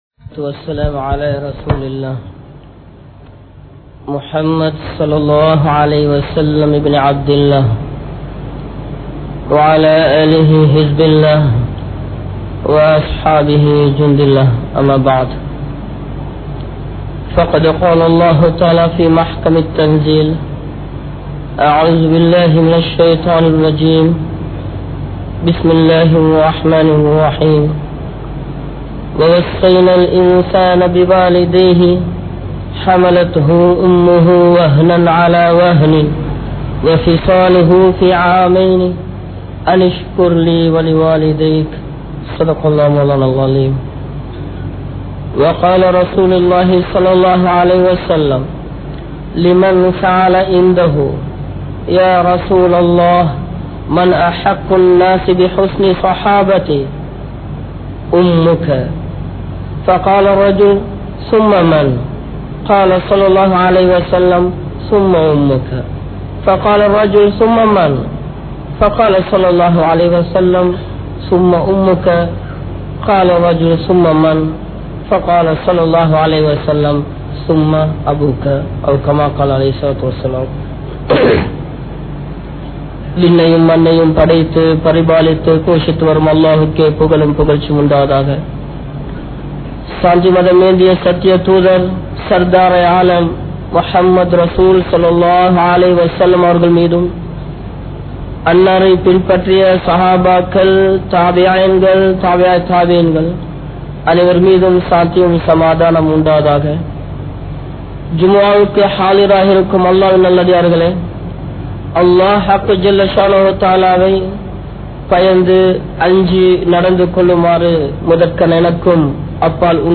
Pillaihalum Indraya Thaaimaarhalum (பிள்ளைகளும் இன்றைய தாய்மார்களும்) | Audio Bayans | All Ceylon Muslim Youth Community | Addalaichenai